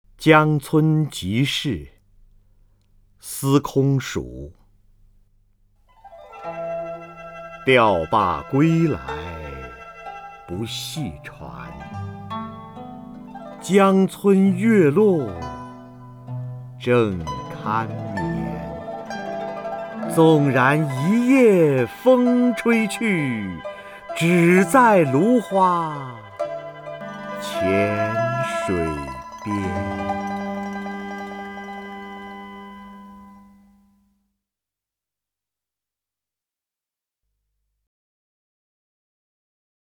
瞿弦和朗诵：《江村即事》(（唐）司空曙) （唐）司空曙 名家朗诵欣赏瞿弦和 语文PLUS